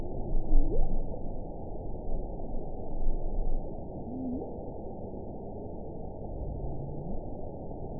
event 919327 date 12/30/23 time 18:05:31 GMT (1 year, 11 months ago) score 6.42 location TSS-AB07 detected by nrw target species NRW annotations +NRW Spectrogram: Frequency (kHz) vs. Time (s) audio not available .wav